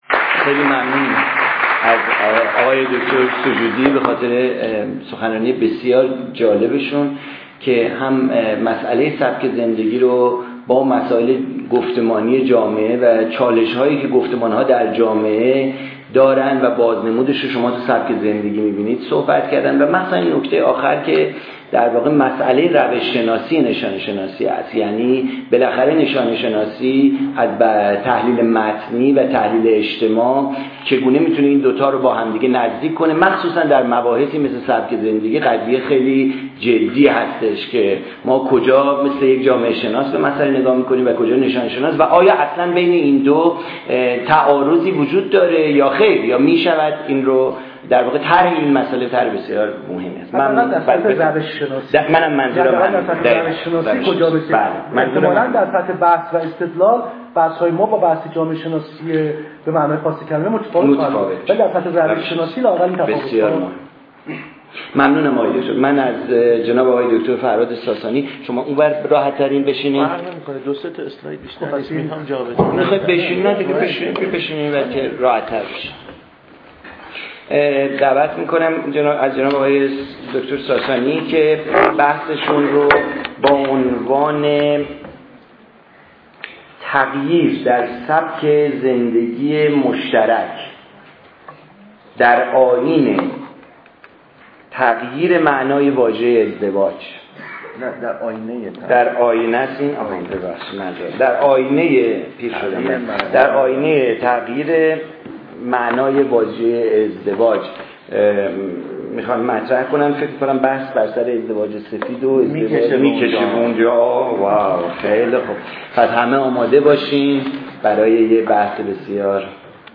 این همایش اسفند ماه ۹۳ در مرکز دایرهالمعارف بزرگ اسلامی برگزار شد.